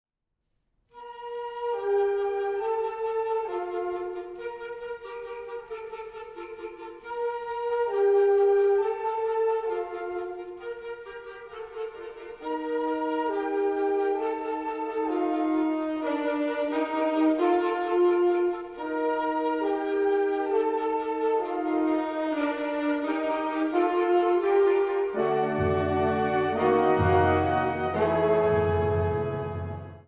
Kategorie Blasorchester/HaFaBra
Unterkategorie Konzertmusik